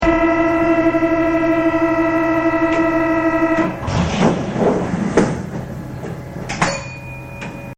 Departure Procedure and Sounds
Buzzing indicates the closing doors (
fermetureporte.mp3